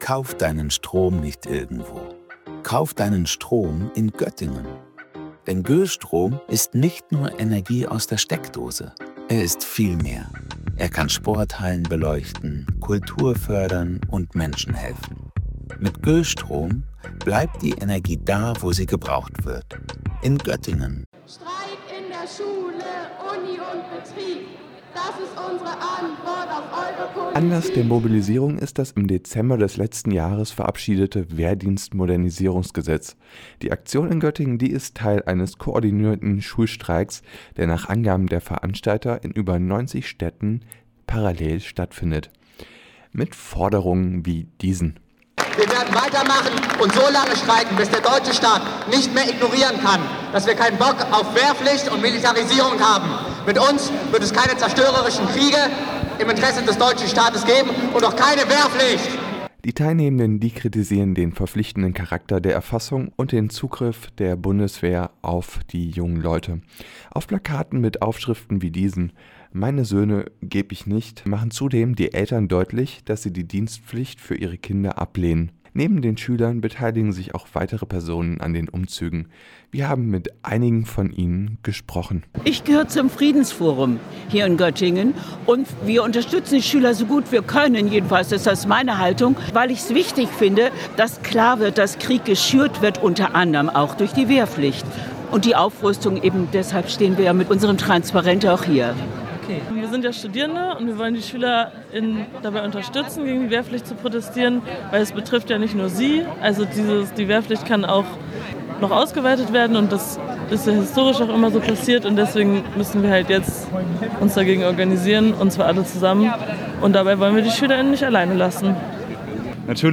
Beiträge > In mehreren Protestzügen durch Göttingen - Demonstration gegen Wehrpflicht - StadtRadio Göttingen